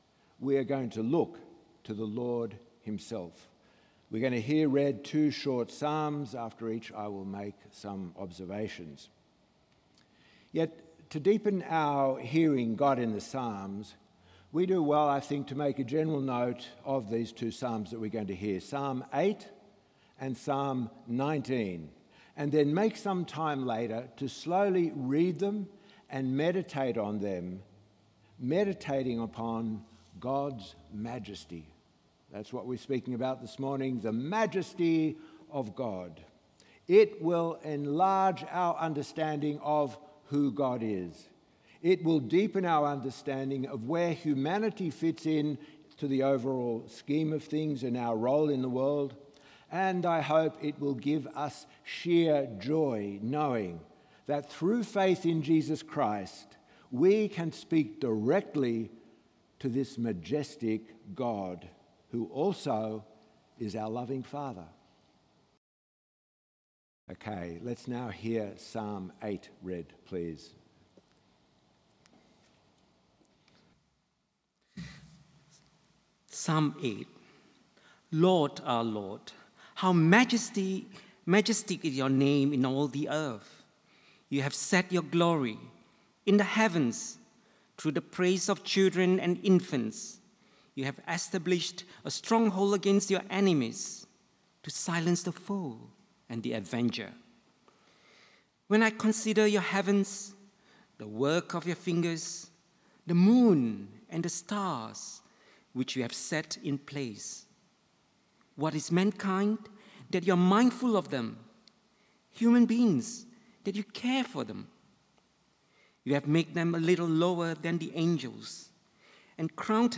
Sermon 3 August 2.mp3